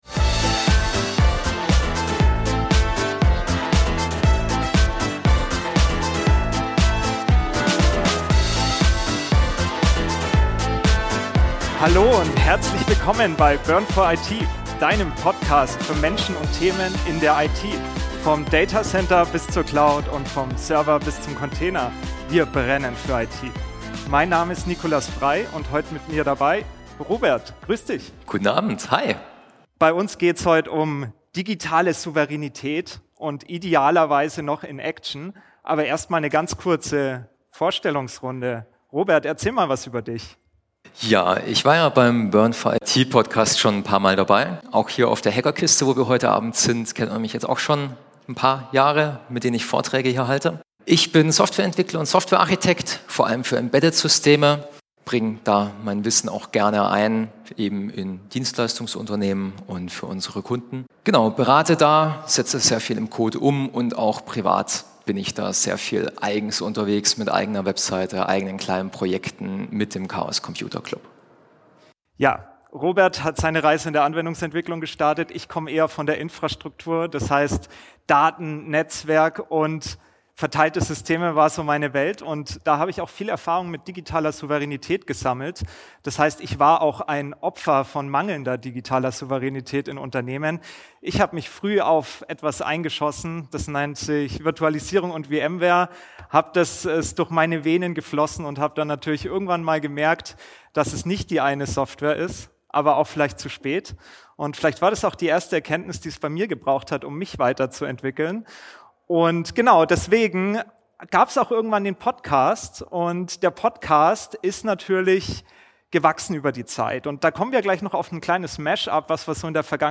In dieser Folge durchdringen wir die Strukturen der Abhängigkeit im digitalen Zeitalter. In einer regen Diskussion mit dem Publikum, ist daraus die erste Live-Podcast-Folge von BURN 4 IT entstanden.